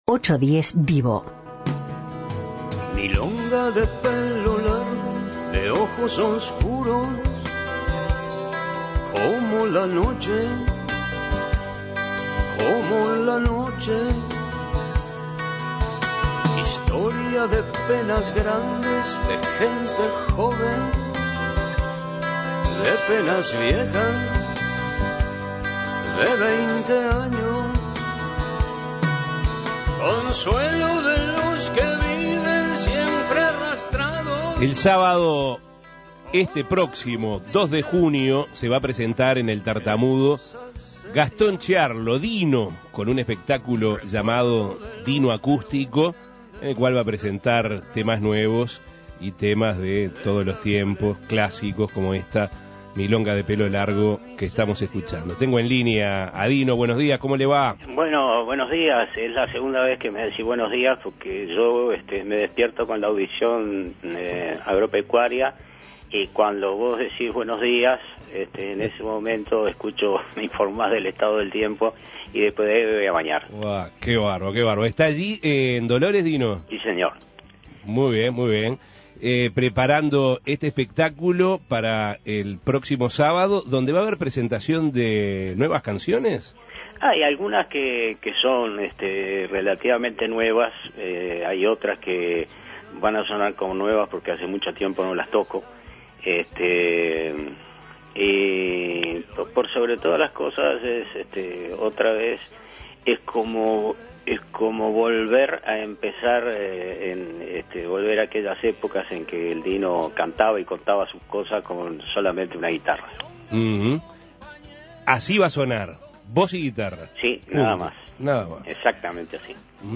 Gastón Ciarlo, más conocido como "Dino" dialogó con 810 Vivo Avances, tendencias y actualidad, con motivo del toque que hará en El Tartamudo el 2 de junio.